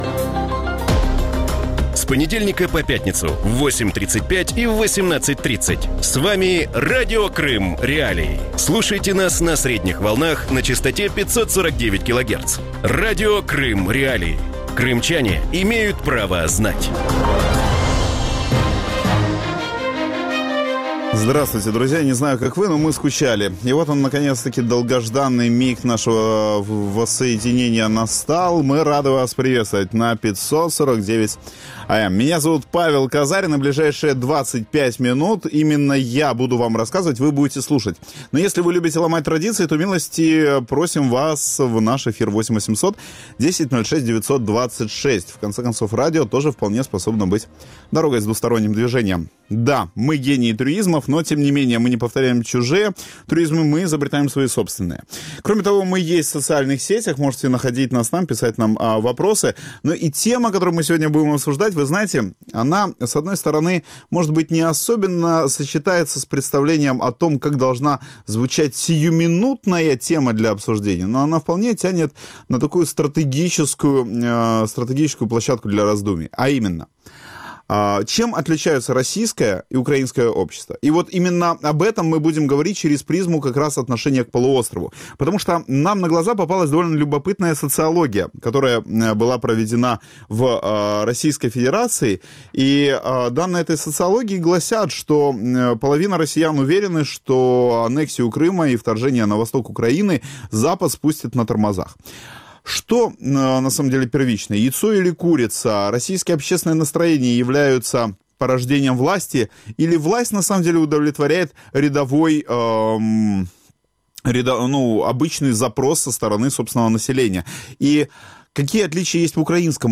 У вечірньому ефірі Радіо Крим.Реалії обговорюють, чи можуть росіяни визнати провину за анексію Криму і розв'язування війни на Донбасі. Чим є російське суспільство – архітектором режиму або його заручником.
Ведучий: Павло Казарін.